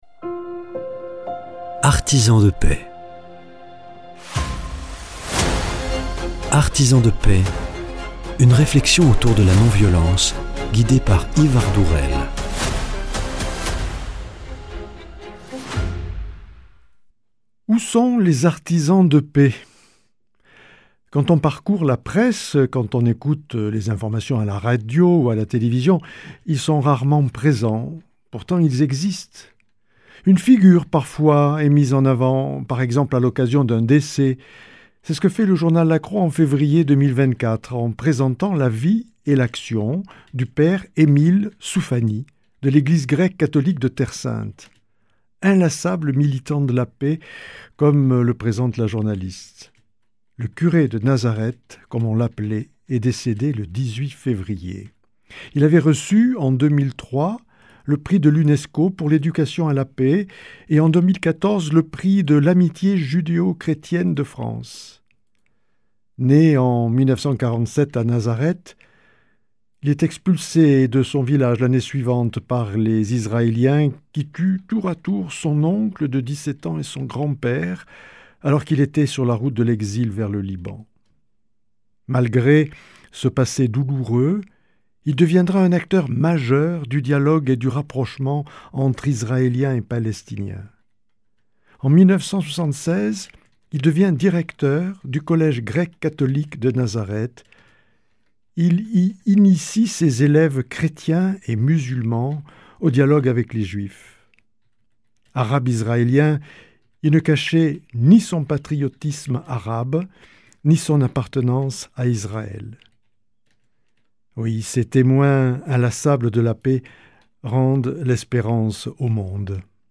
Deuxième entretien avec des étudiantes infirmières qui préparent un séjour au Benin.